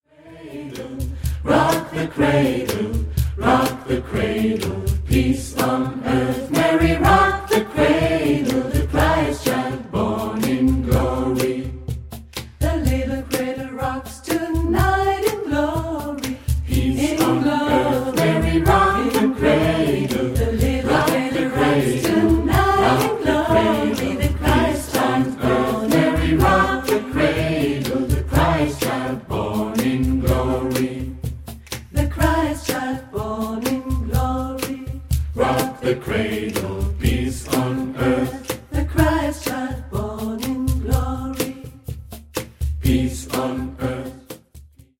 • Neue Lieder für die Advents- und Weihnachtszeit